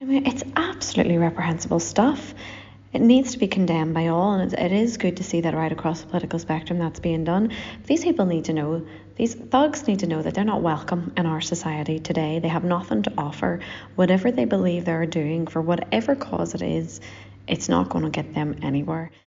Alliance MLA Nuala McAllister sits on the policing board: